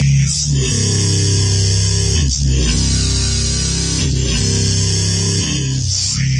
Thrasher Drum Loop 150bpm
描述：Rock Drums made in FL Studio
标签： 150 bpm Rock Loops Drum Loops 2.15 MB wav Key : Unknown
声道立体声